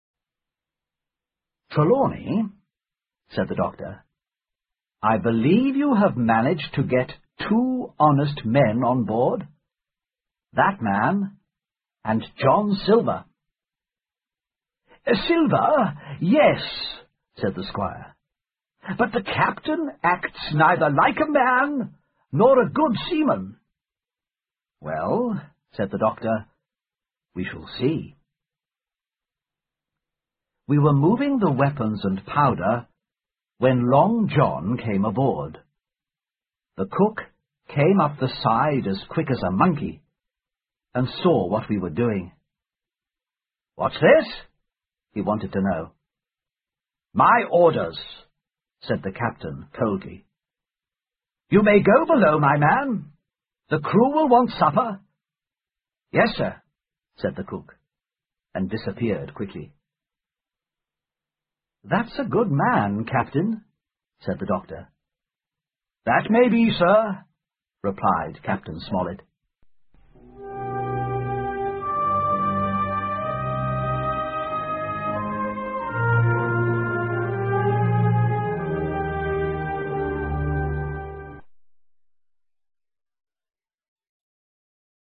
在线英语听力室《金银岛》第六章 船和船员(4)的听力文件下载,《金银岛》中英双语有声读物附MP3下载